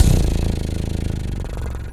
pgs/Assets/Audio/Animal_Impersonations/cat_2_purr_07.wav at master
cat_2_purr_07.wav